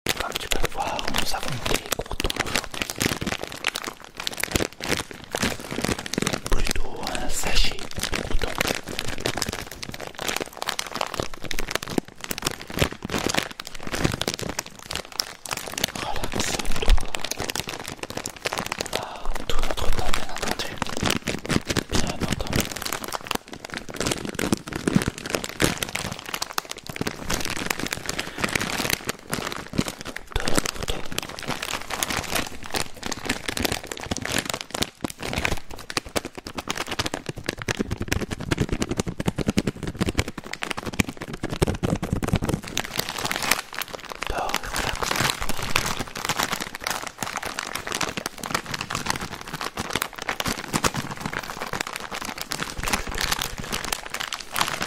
ASMR AVEC UN SACHET DE Sound Effects Free Download